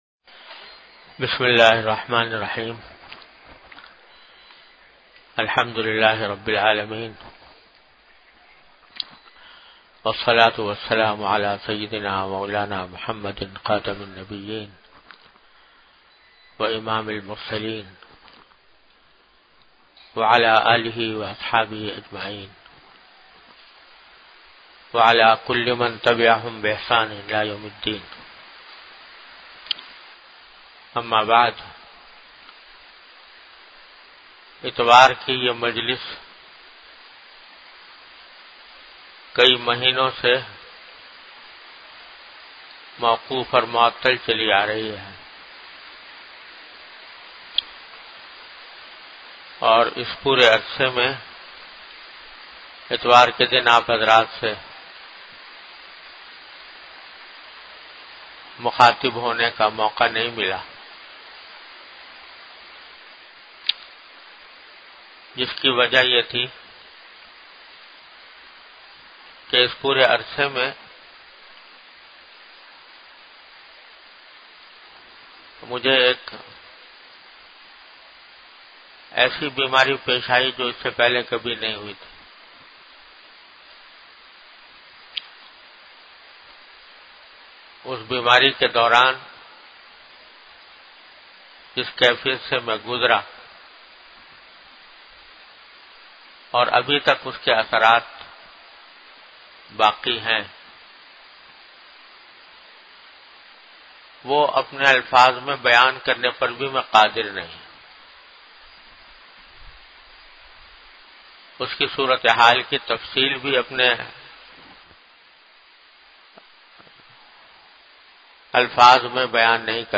An Islamic audio bayan by Hazrat Mufti Muhammad Taqi Usmani Sahab (Db) on Bayanat. Delivered at Darululoom Karachi.